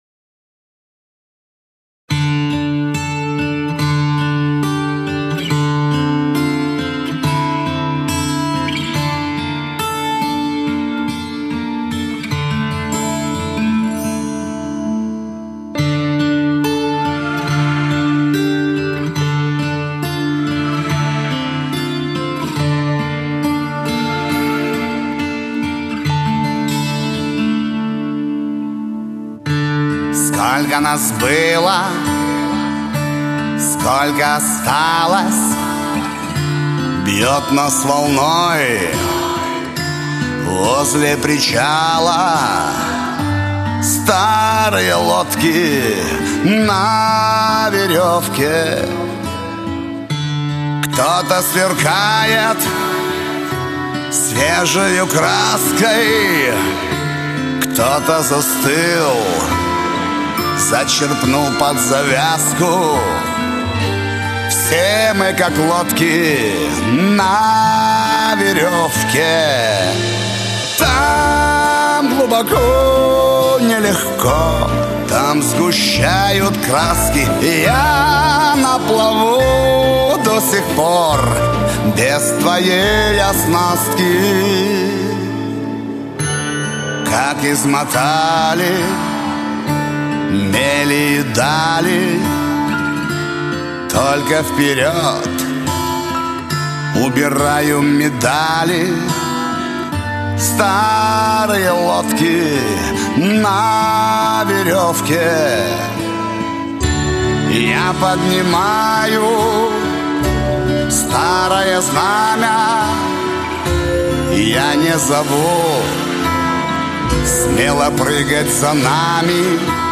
Эстрада, шансон